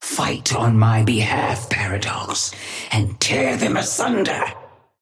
Sapphire Flame voice line - Fight on my behalf, Paradox, and tear them asunder!
Patron_female_ally_chrono_start_05.mp3